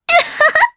Epis-Vox-Laugh_jp.wav